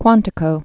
(kwäntĭ-kō)